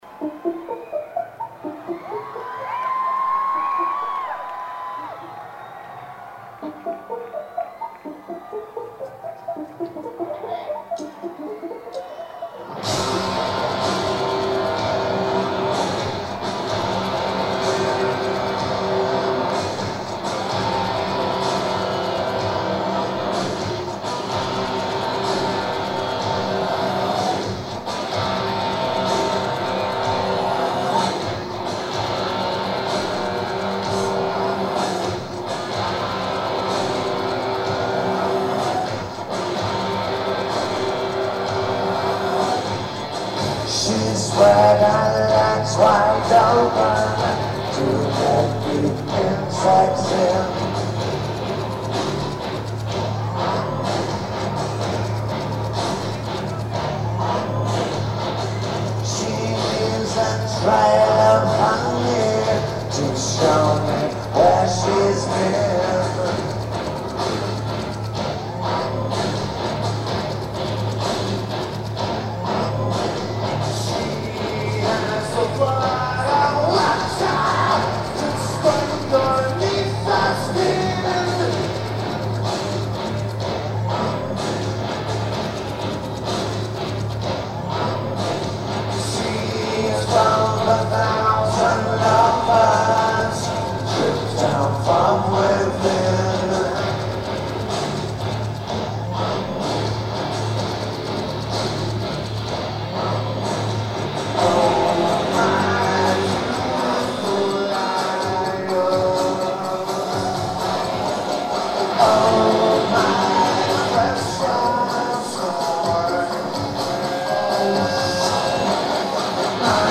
CocaCola Star Lake Amphitheater
Average recording.